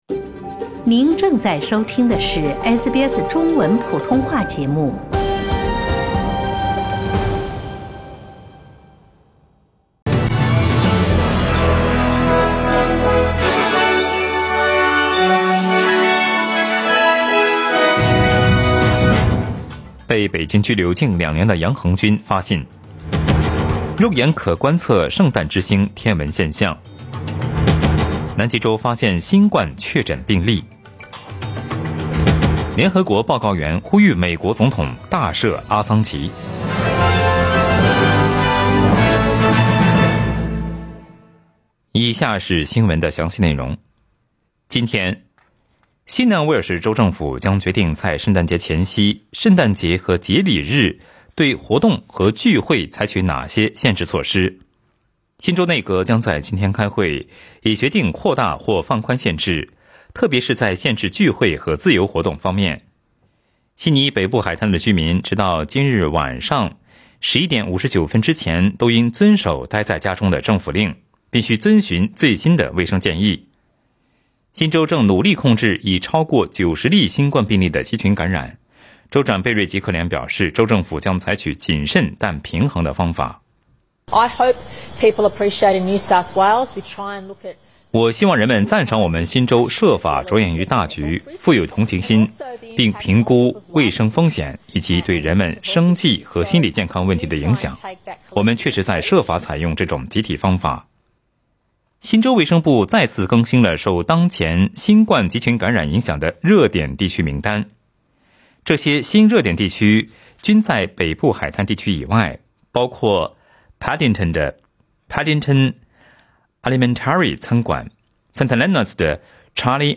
SBS早新聞（12月23日）